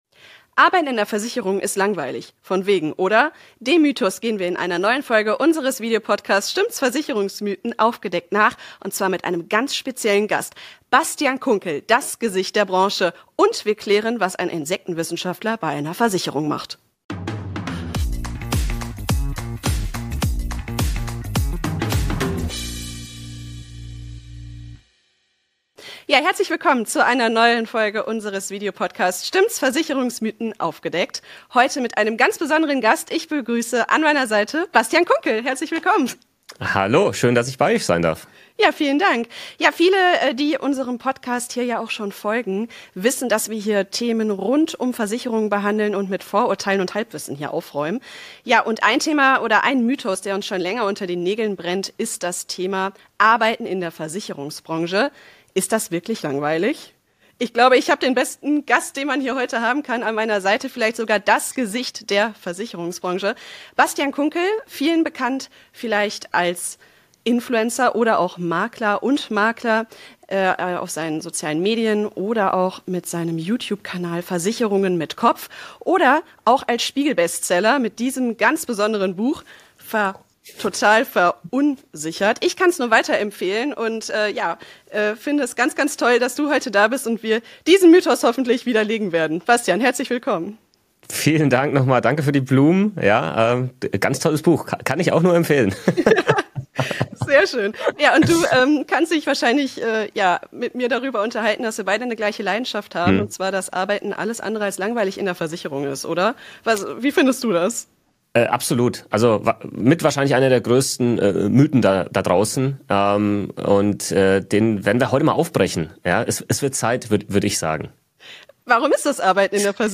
Ein inspirierendes Gespräch über Leidenschaft, Sinn und Karrierechancen in einer Branche, die viel mehr zu bieten hat, als man denkt.